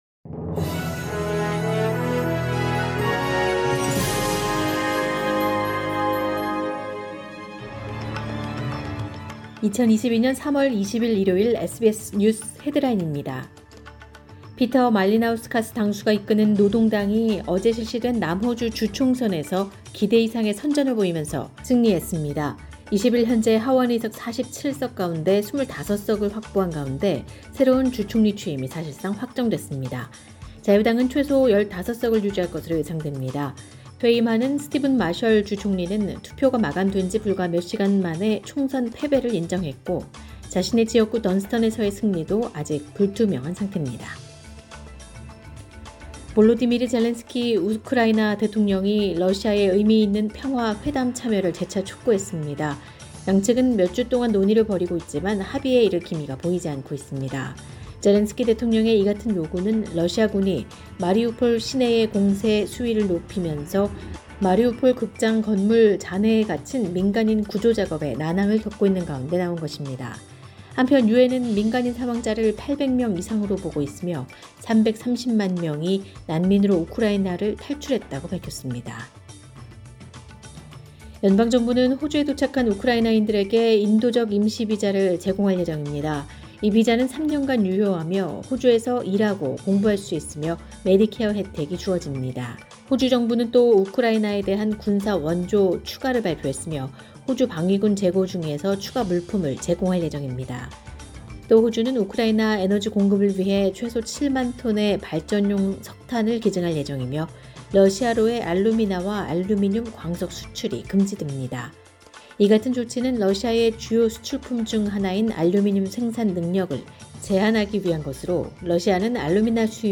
2022년 3월 20일 일요일 SBS 뉴스 헤드라인입니다.